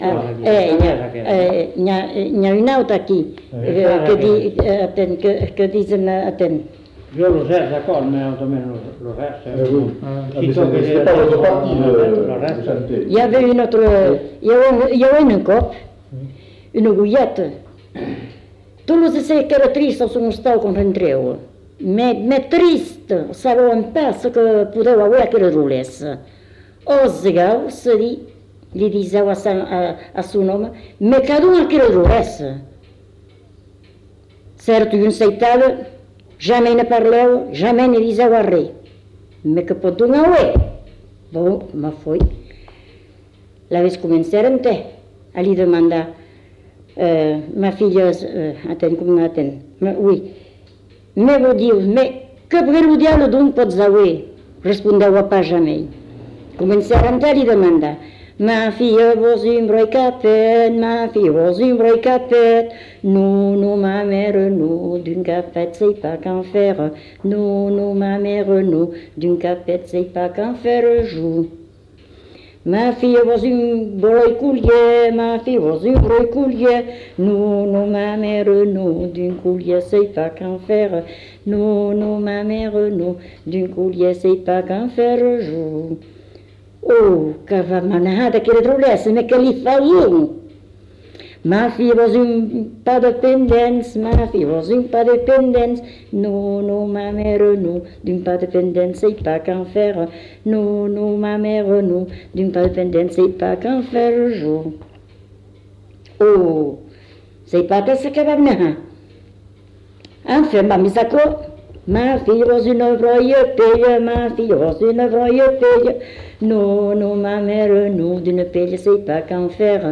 Lieu : Cazalis
Genre : chant
Effectif : 1
Type de voix : voix de femme
Production du son : chanté